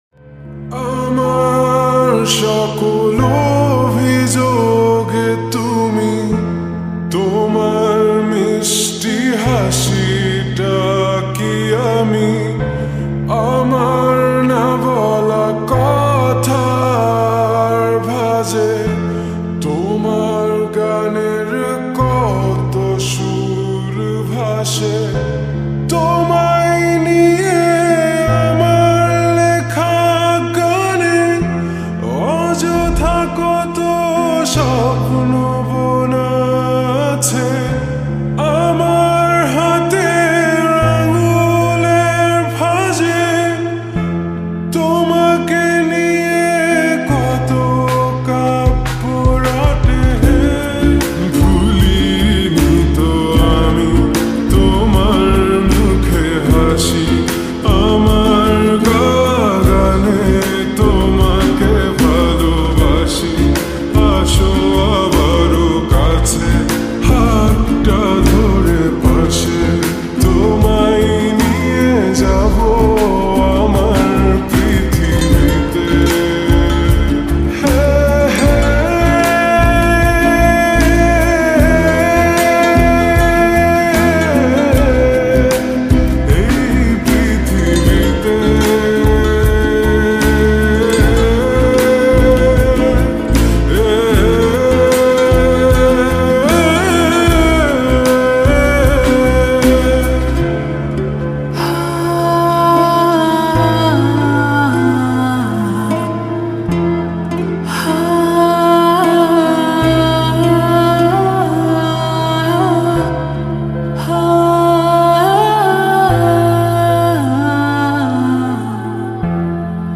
Slowed And Reverb New Bangla Lofi Song